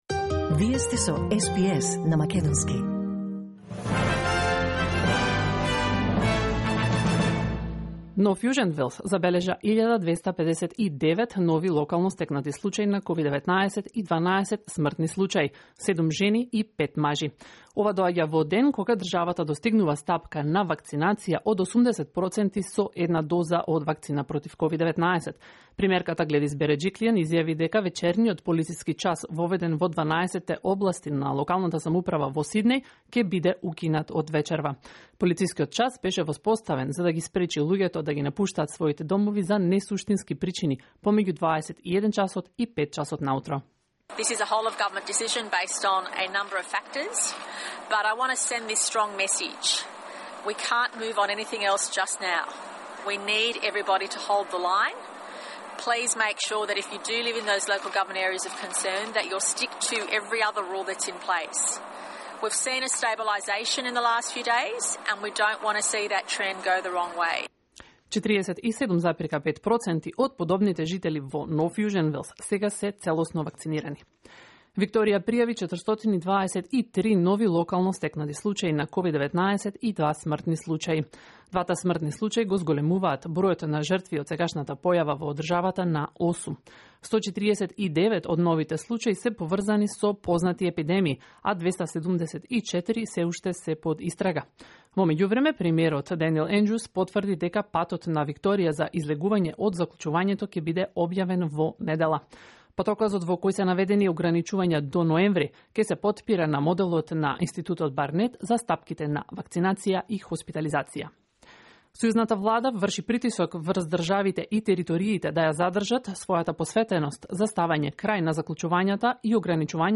SBS News in Macedonian 15 September 2021